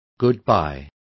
Complete with pronunciation of the translation of goodbyes.